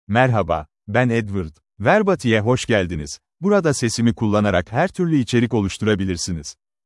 Edward — Male Turkish (Turkey) AI Voice | TTS, Voice Cloning & Video | Verbatik AI
Edward is a male AI voice for Turkish (Turkey).
Voice sample
Listen to Edward's male Turkish voice.
Edward delivers clear pronunciation with authentic Turkey Turkish intonation, making your content sound professionally produced.